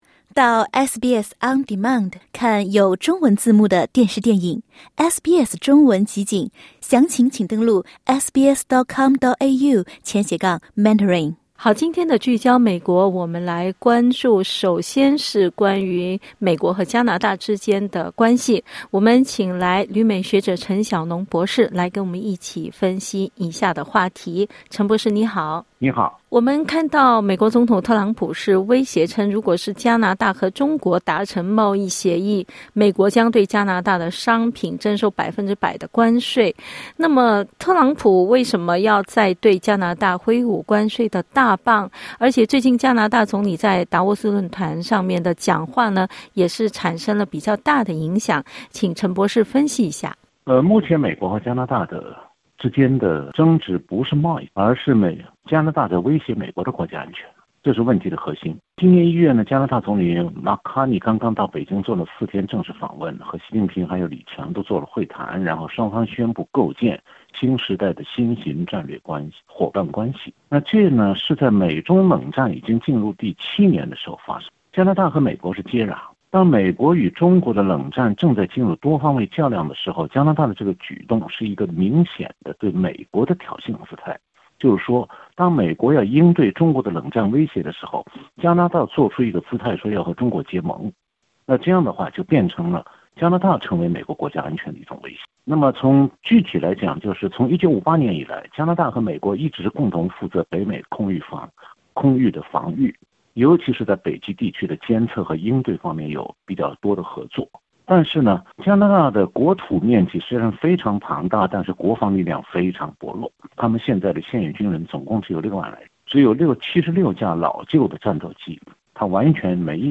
点击音频收听详细采访 本节目仅为嘉宾观点 欢迎下载应用程序SBS Audio，订阅Mandarin。